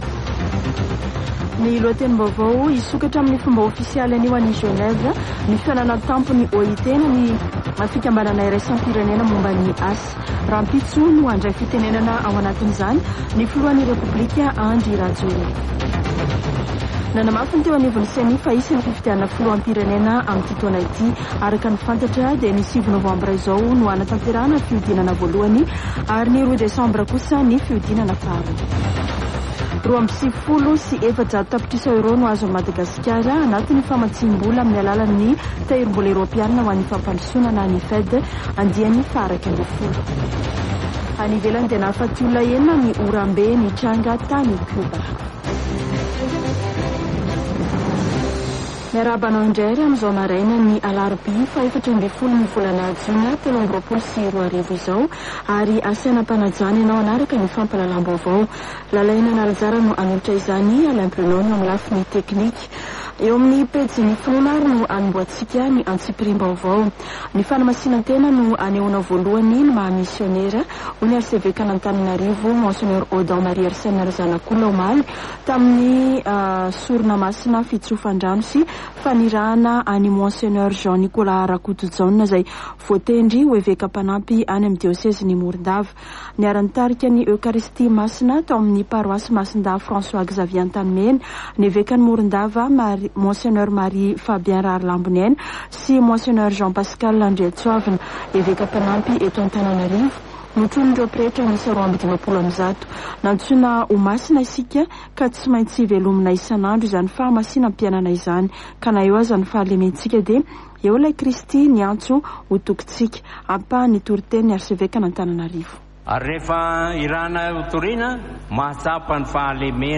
[Vaovao maraina] Alarobia 14 jona 2023